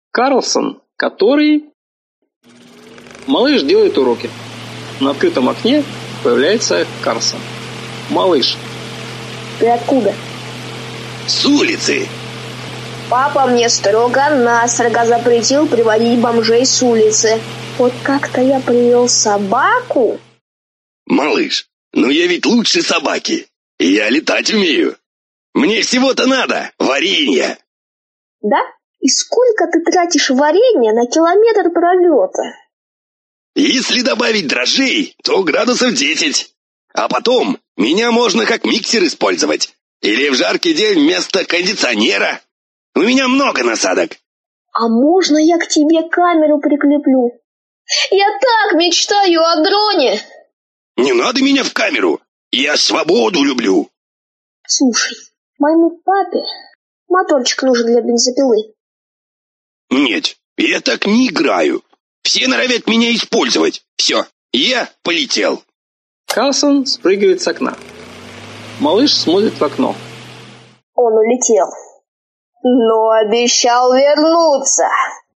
Аудиокнига Школоприколы. Аудиоспектакль | Библиотека аудиокниг